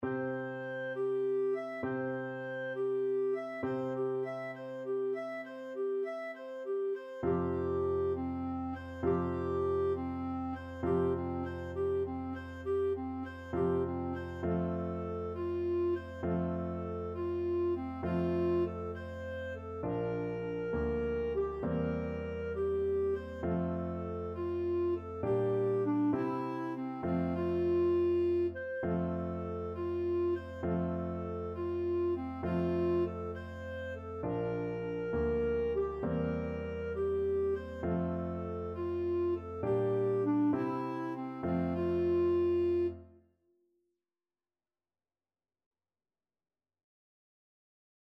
Classical Beethoven, Ludwig van Shepherd's Song (from Symphony No.6) Clarinet version
6/8 (View more 6/8 Music)
Allegretto
Classical (View more Classical Clarinet Music)
beethoven_shepherds_song_CL.mp3